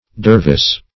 Dervish \Der"vish\, Dervise \Der"vise\, Dervis \Der"vis\, n.